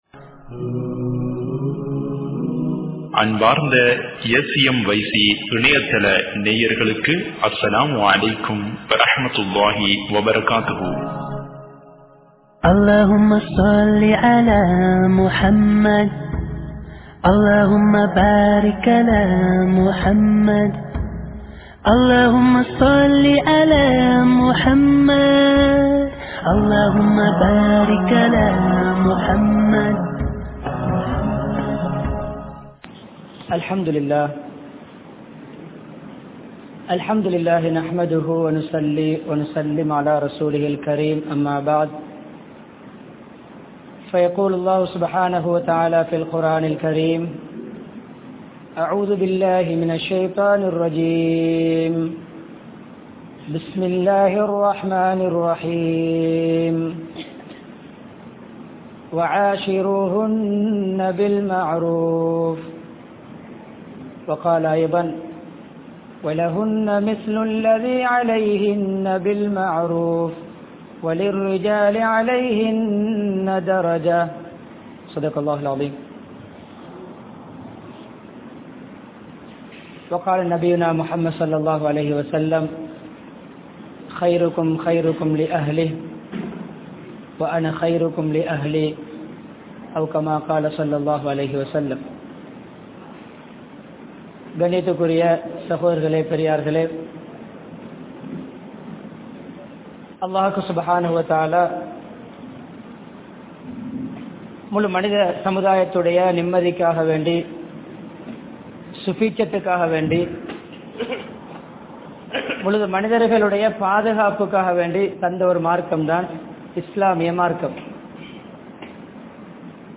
Kanavan Manaivien Urimaihal (கணவன் மணைவியின் உரிமைகள்) | Audio Bayans | All Ceylon Muslim Youth Community | Addalaichenai